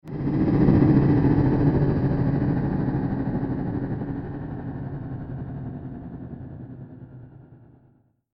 دانلود آهنگ کشتی 6 از افکت صوتی حمل و نقل
دانلود صدای کشتی 6 از ساعد نیوز با لینک مستقیم و کیفیت بالا
جلوه های صوتی